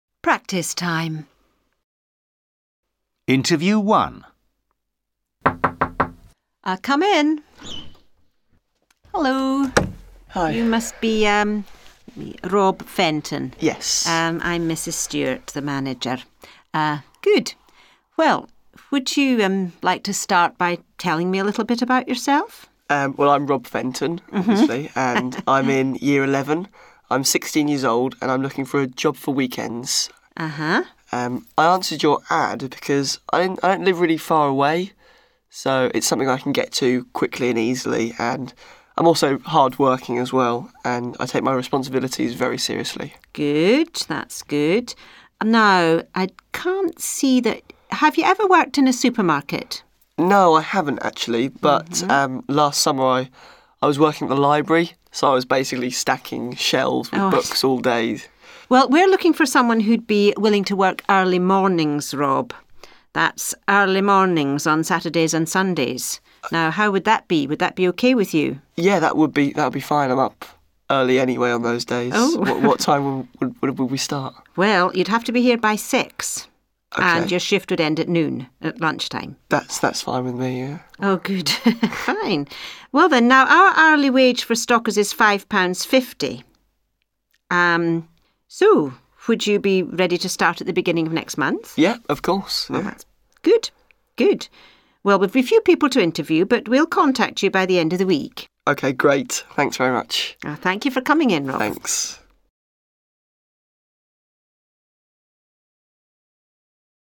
Interview-1-short-version.mp3